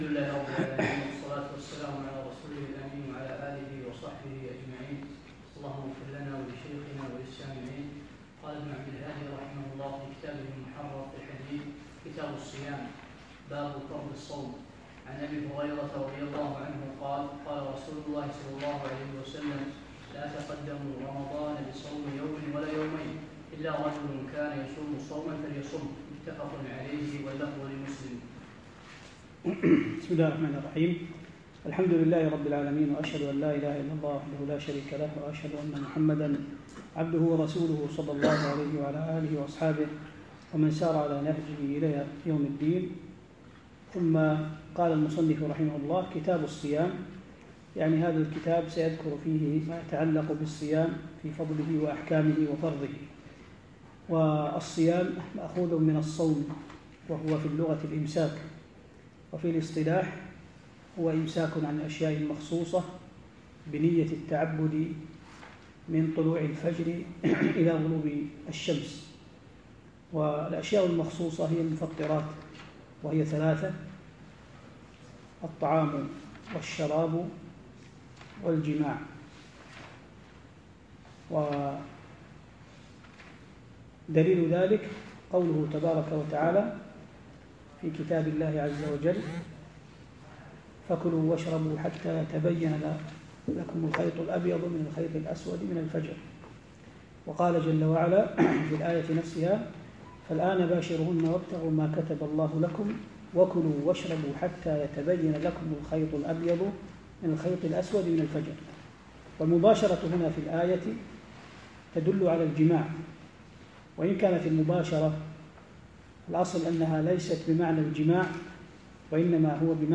الدرس الرابع